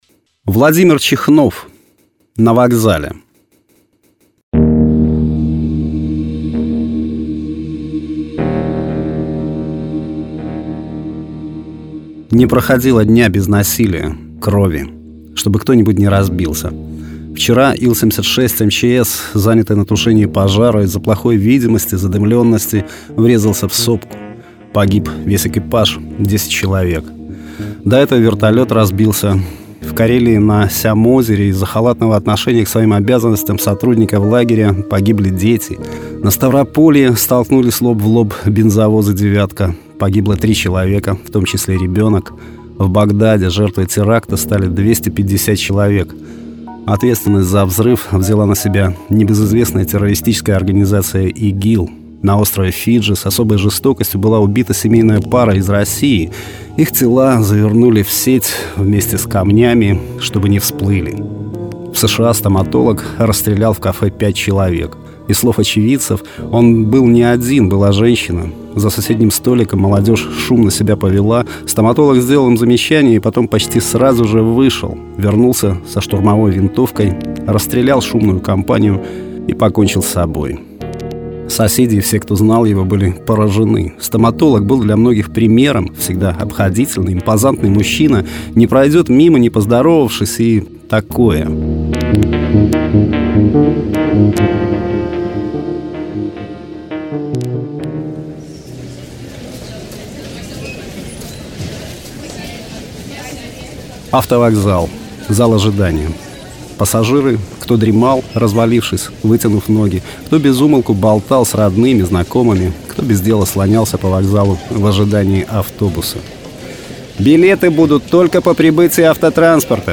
Аудиорассказ: Владимир Чихнов.
Жанр: Современная короткая проза
Качество: mp3, 256 kbps, 44100 kHz, Stereo